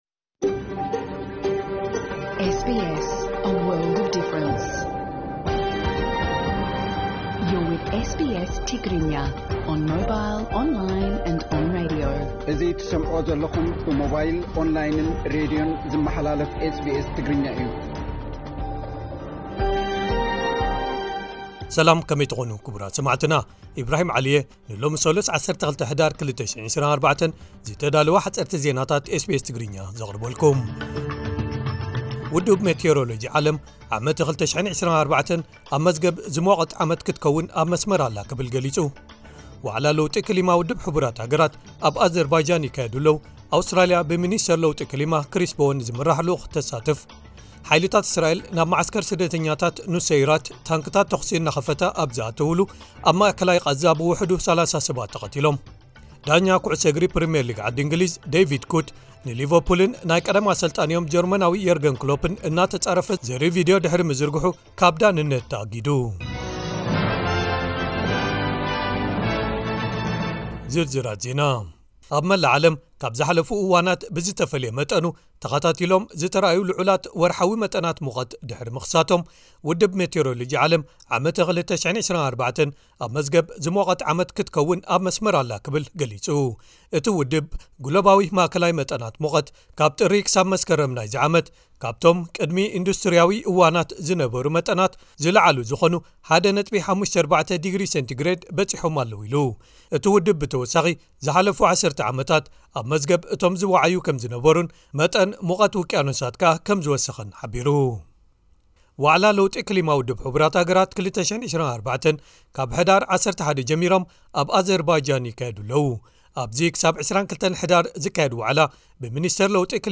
ሓጸርቲ ዜናታት ኤስ ቢ ኤስ ትግርኛ (12 ሕዳር 2024)